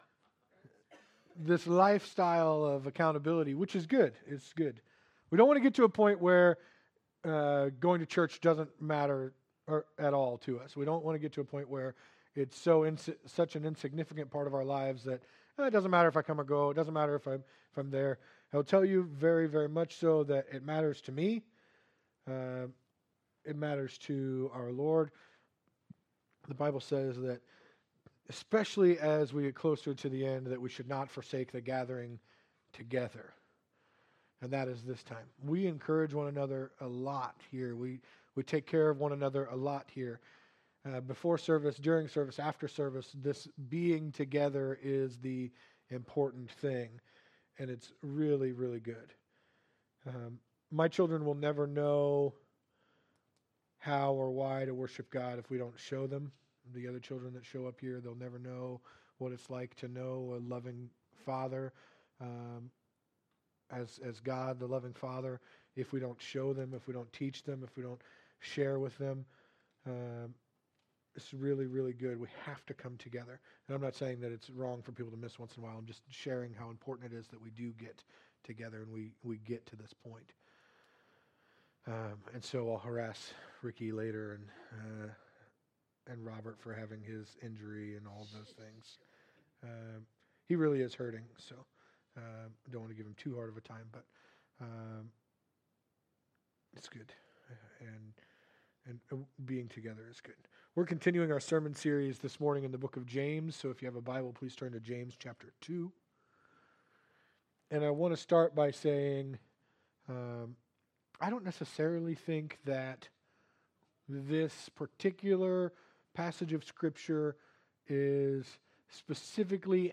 Sermons | Calvary Foursquare Church